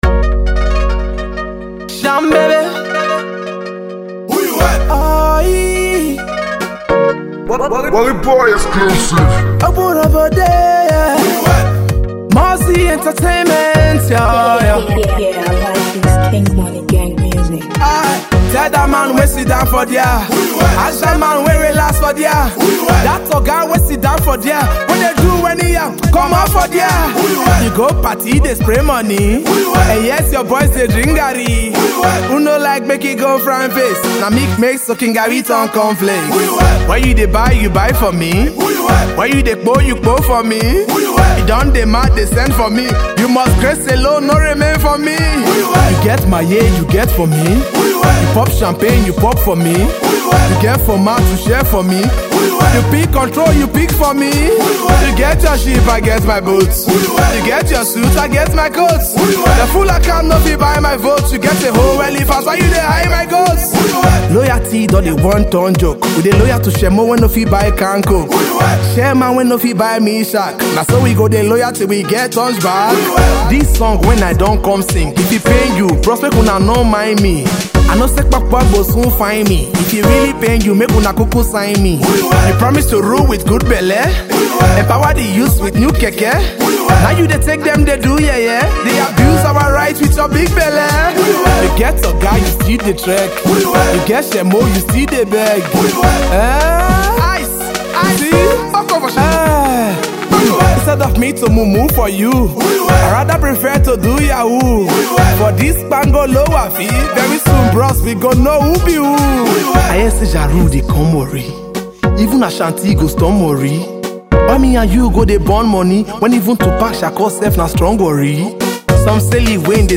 Pidgin rap song